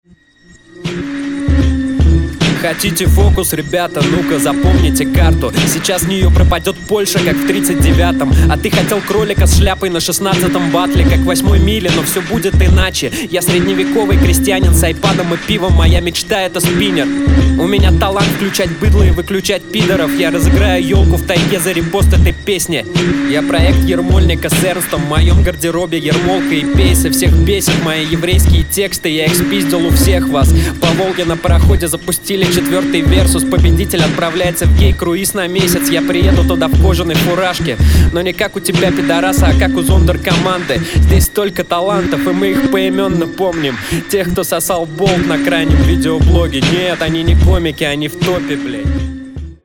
Исполнено харизматично, при всей сдержанности атмосферы